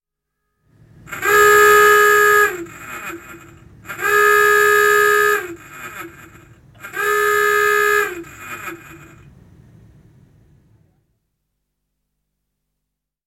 Klaxon.mp3